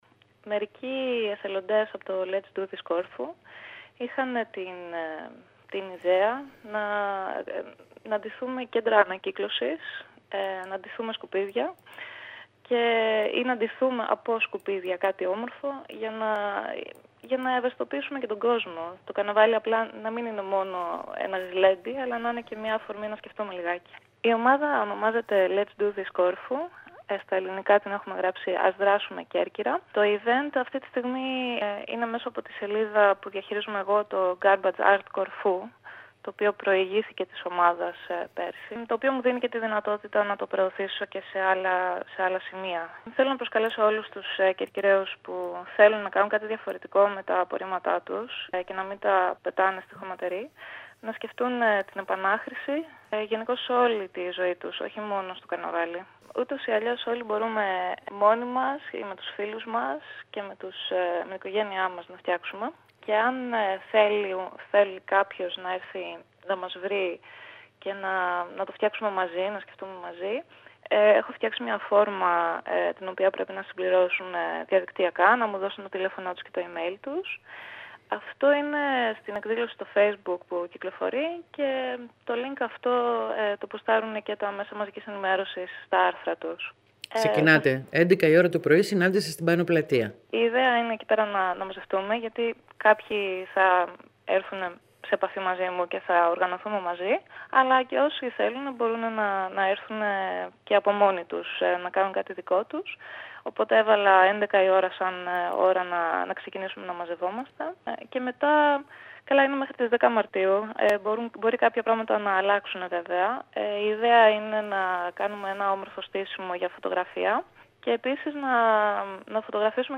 απο την συγκεκριμένη ομάδα μίλησε στην ΕΡΤ Κέρκυρας